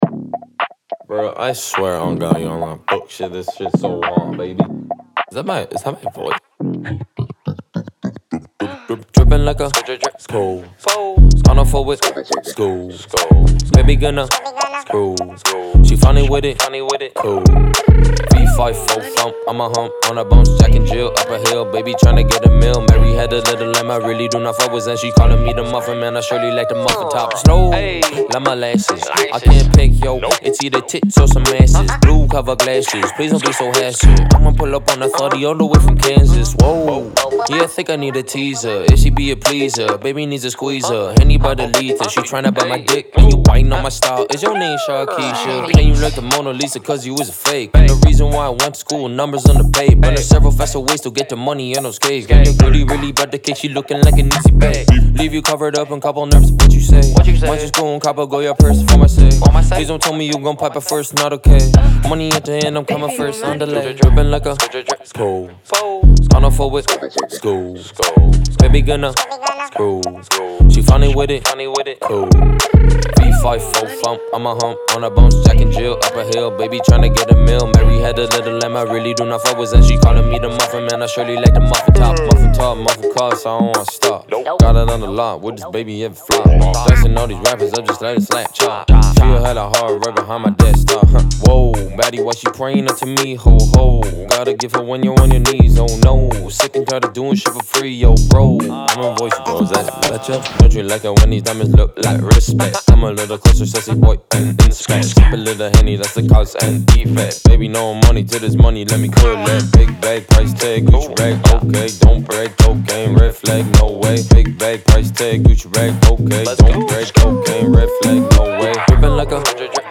BPM105-105
Audio QualityPerfect (High Quality)
Rap song for StepMania, ITGmania, Project Outfox
Full Length Song (not arcade length cut)